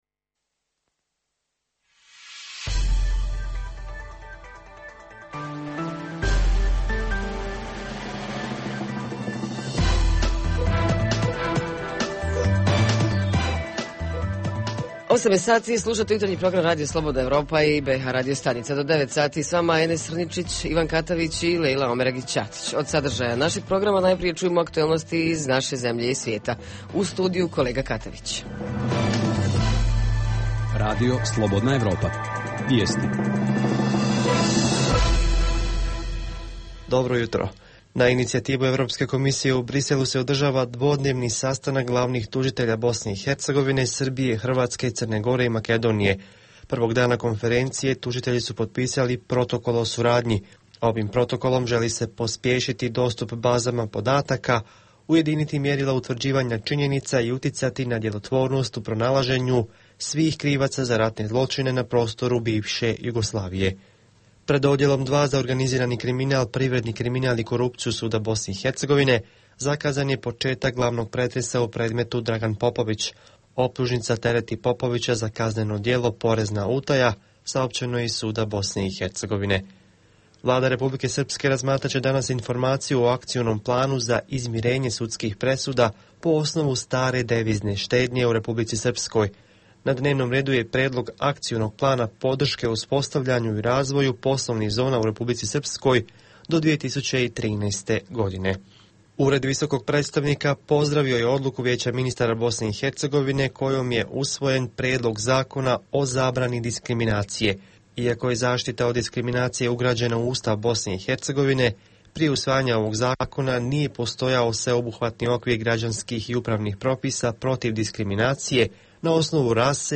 Jutarnji program za BiH koji se emituje uživo. Tema ovog jutra su poljoprivrednici, njihove zadruge i udruženja – kako da budu bolje organizirani i uspješniji u proizvodnji i ostvarivanju svojih prava.
Redovni sadržaji jutarnjeg programa za BiH su i vijesti i muzika.